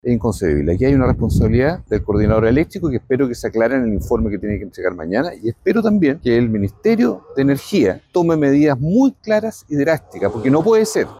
Y también en el oficialismo, el senador socialista, Juan Luis Castro, tildó de “escandaloso” que cada ciertos días se conozcan nuevas faltas en el servicio eléctrico nacional.